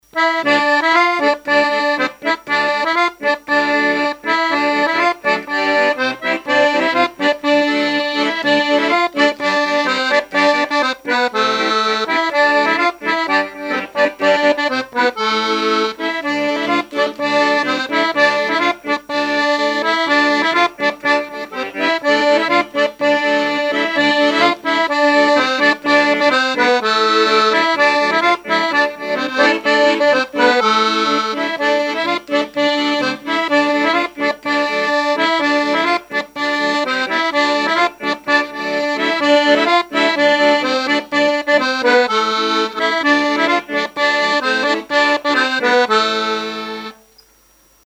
Saint-Christophe-du-Ligneron
gestuel : à marcher ; danse : ronde : grand'danse
Pièce musicale inédite